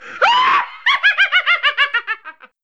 cackling witch sound file and put it onto the CIRCUITPY drive on your computer.
witch.wav